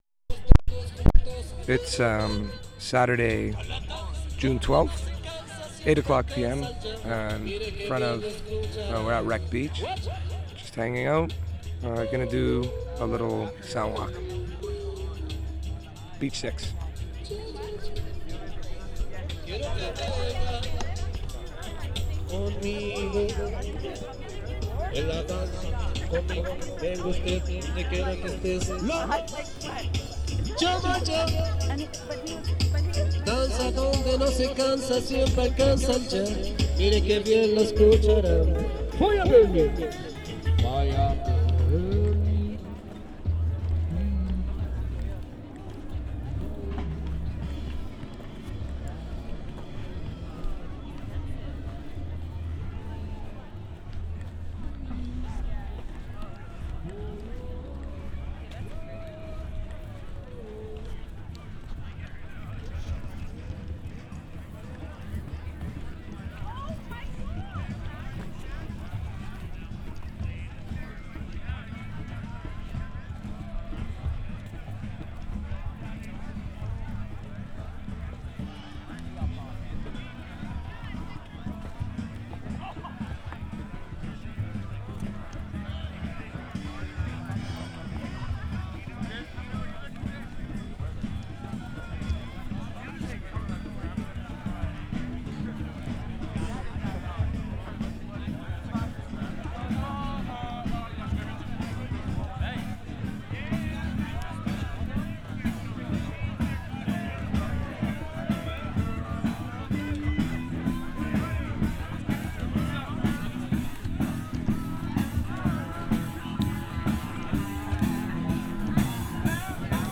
WRECK BEACH, JUNE 12/2010
Soundwalk, 4:42
1. *Soundwalk*, Track ID @ intro, Rastafari singing reggae in Spanish at beginning, thousands of people are on the beach, @ 1:20 - band performing a Doors tune "Roadhouse Blues" to an audience of about 40-50 people, sunny, gorgeous day, people playing frisbee @ 3:15, Storytelling @ 3:35 (explicit), conversation in Spanish @ 4:35.